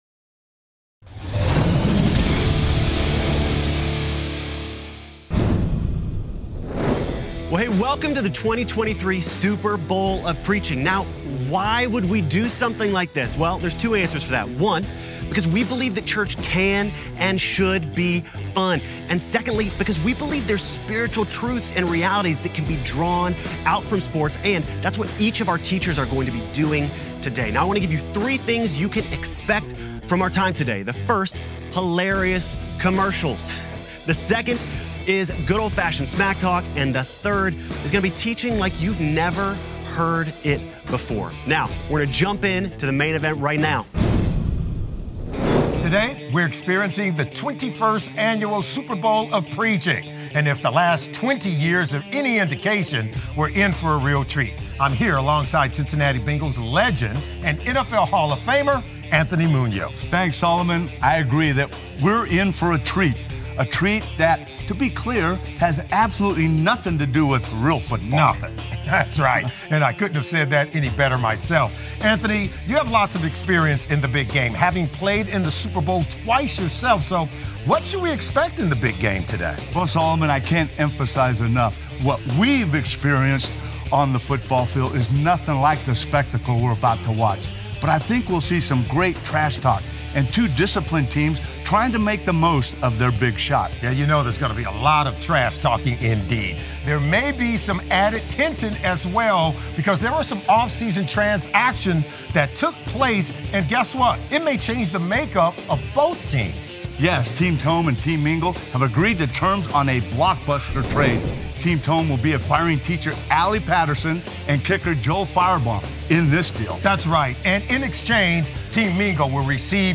Join us for an unconventional, schticky, preaching competition that turns a church service into a spectator sport.